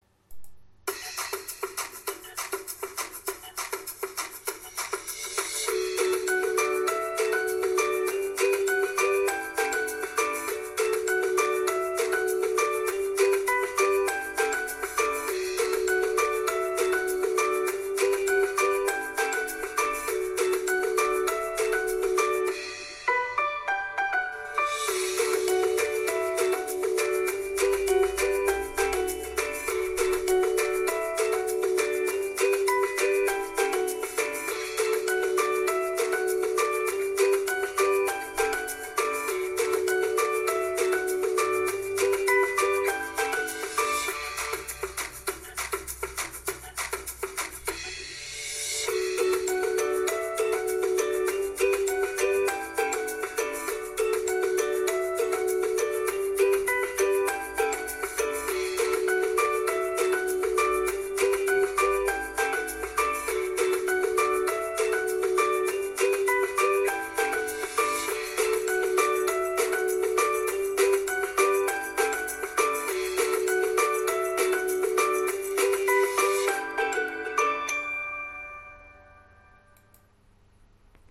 6.-funny-music.mp3